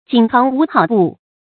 紧行无好步 jǐn xíng wú hǎo bù
紧行无好步发音